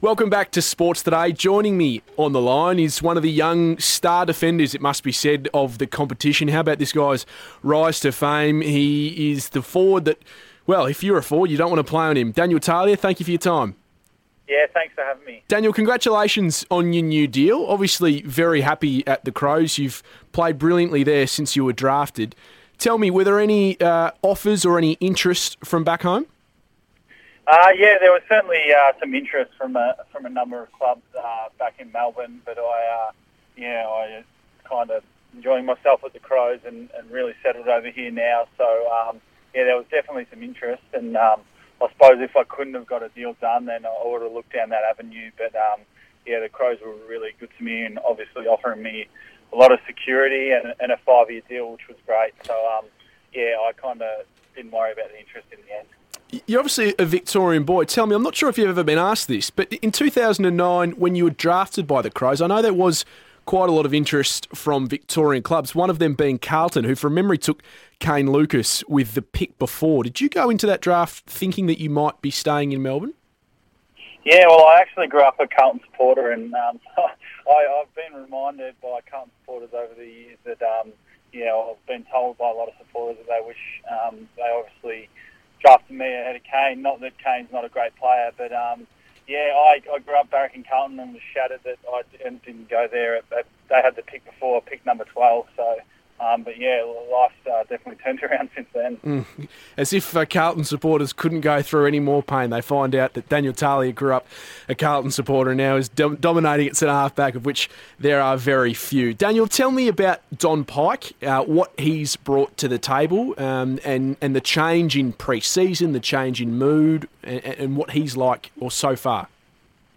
Defender Daniel Talia speaks on 3AW radio after re-signing with Adelaide on a long-term deal